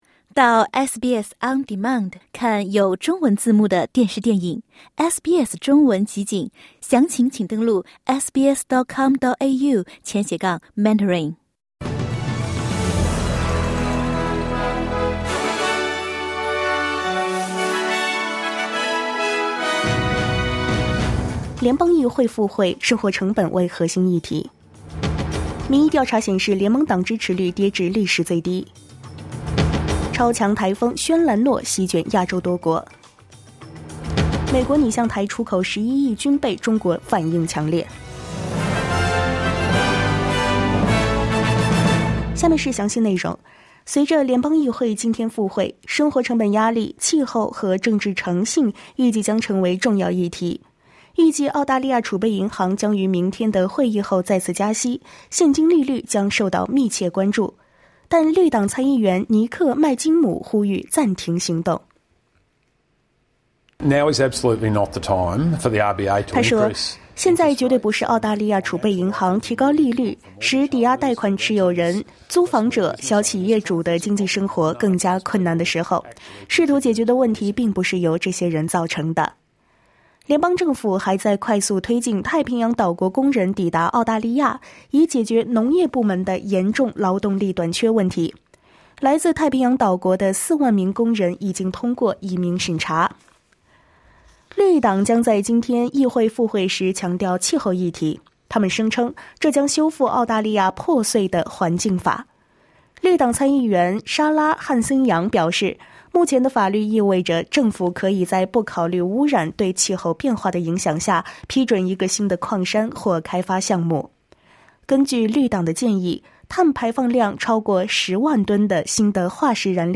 SBS早新闻（9月5日）